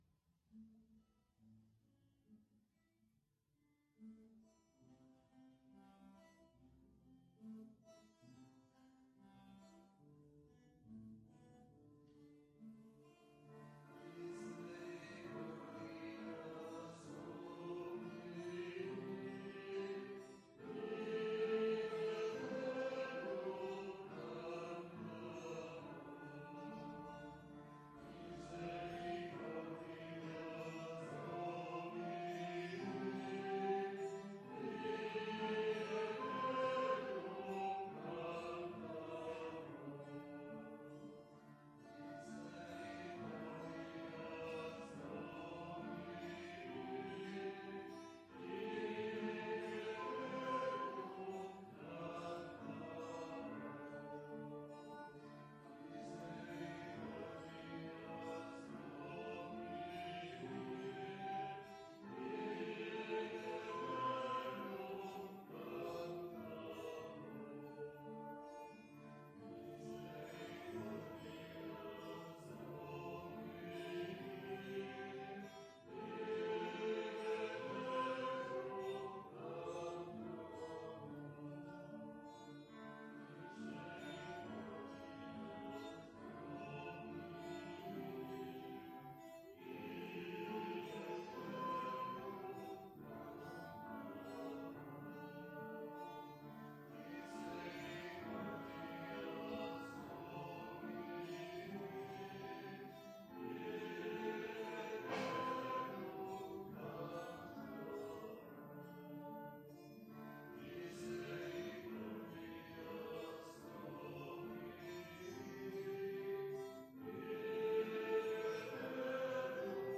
Ermita de Sant Simó - Diumenge 27 de gener de 2019
Vàrem cantar...